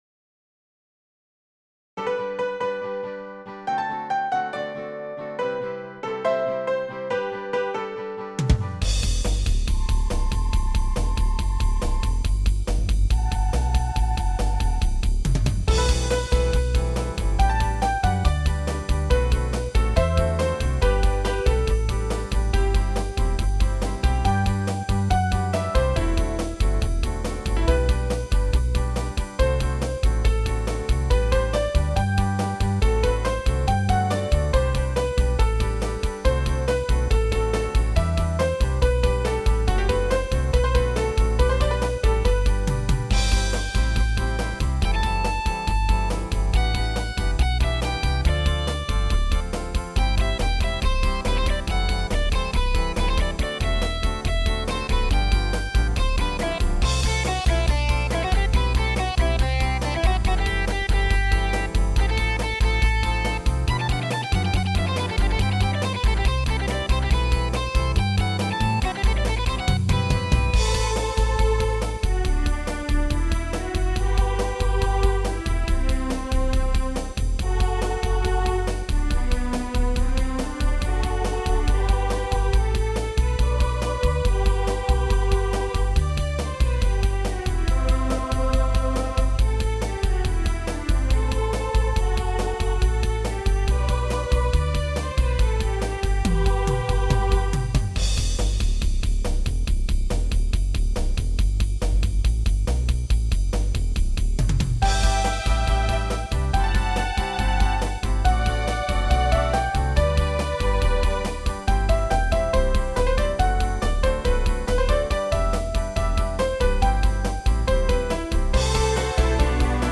ピアノ・アルトサックス・ストリングス他